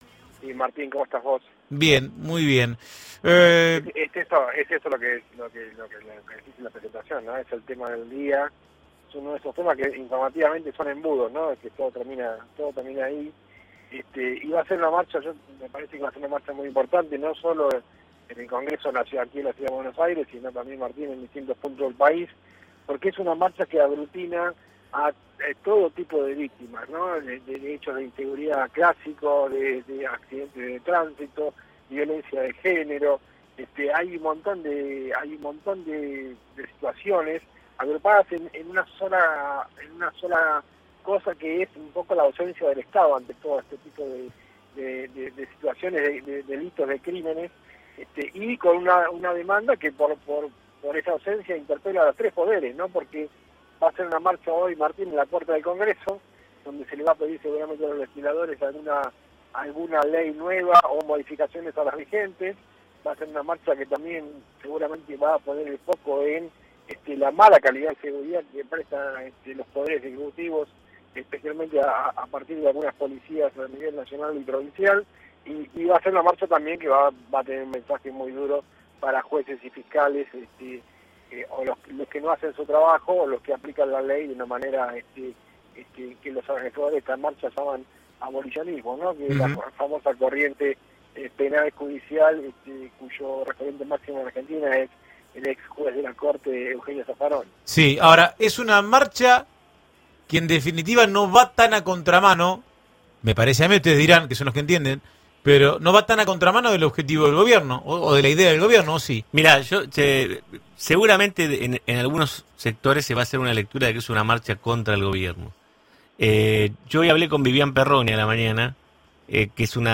En diálogo con LA REDONDA 100.3, el analista político